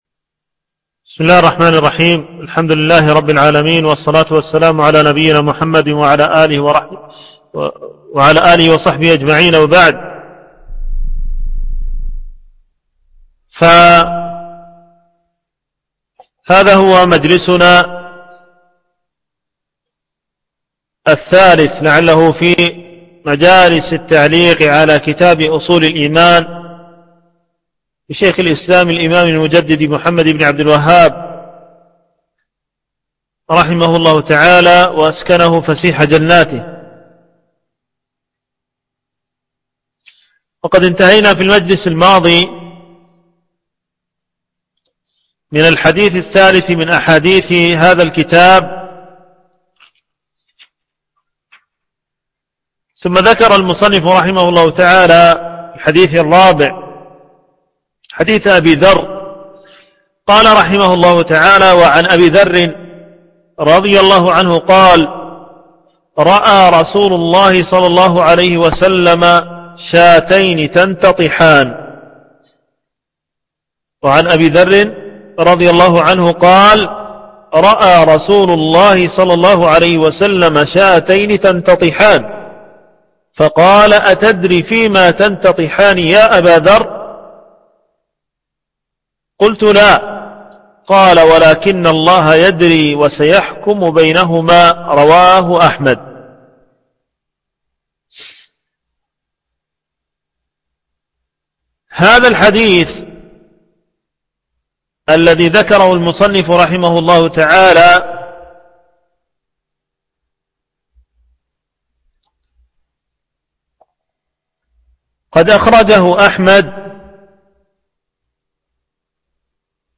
شرح أصول الإيمان - الدرس الرابع